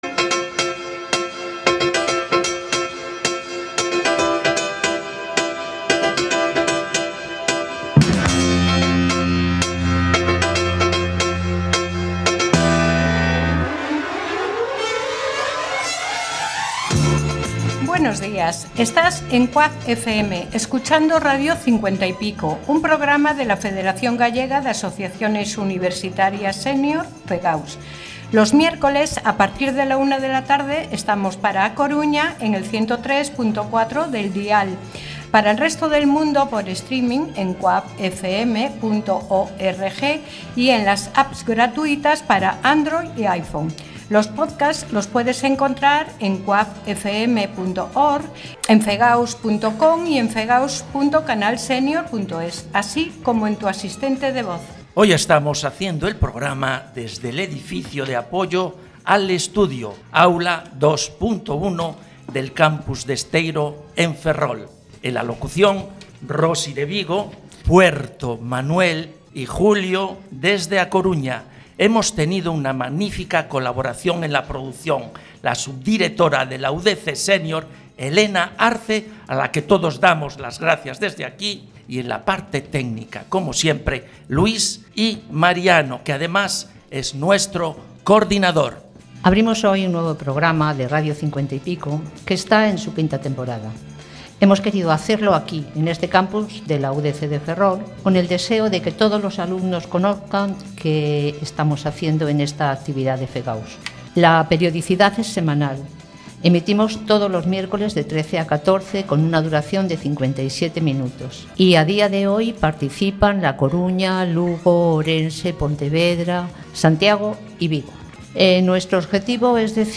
Radio 50 y Pico es un proyecto de comunicación de la Federación Gallega de Asociaciones Universitarias Senior (FEGAUS). Se realiza desde cinco de los siete campus universitarios de Galicia y aborda todo tipo de contenidos de interés, informativos, culturales, de actualidad y de entretenimiento.